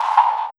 Energy Fx 06.wav